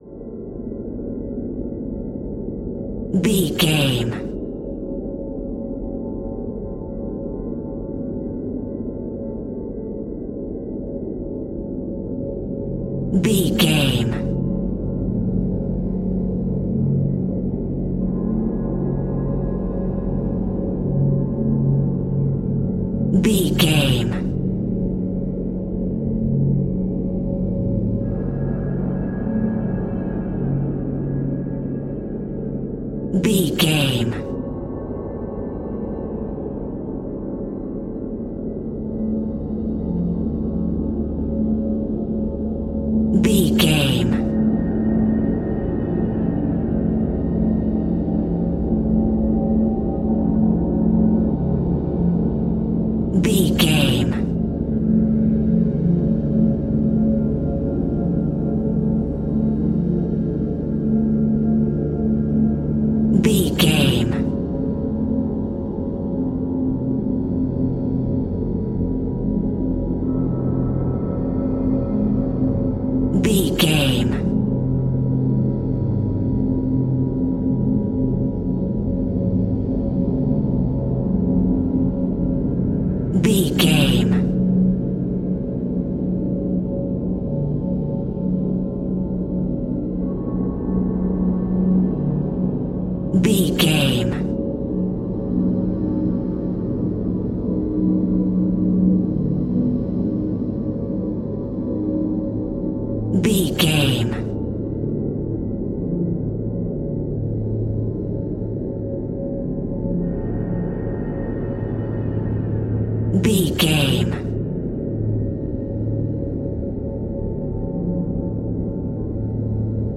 Atonal
Slow
scary
ominous
dark
suspense
eerie
horror
synth
keyboards
ambience
pads
eletronic